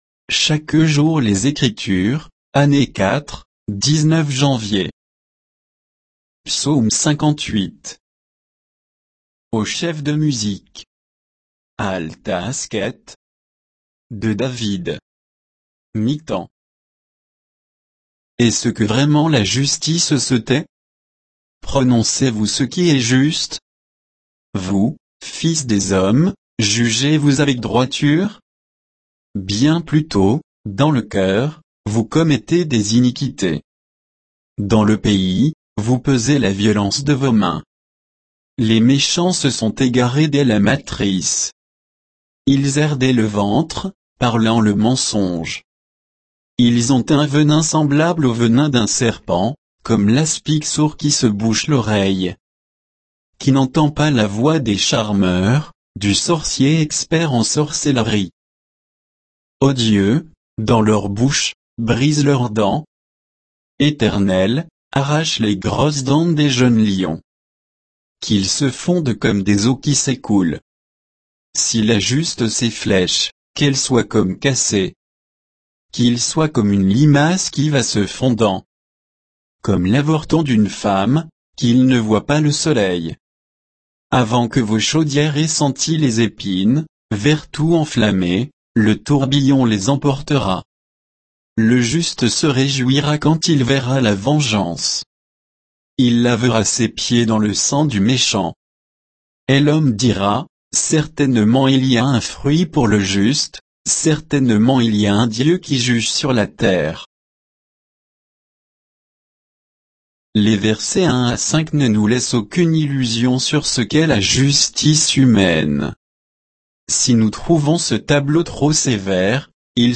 Méditation quoditienne de Chaque jour les Écritures sur Psaume 58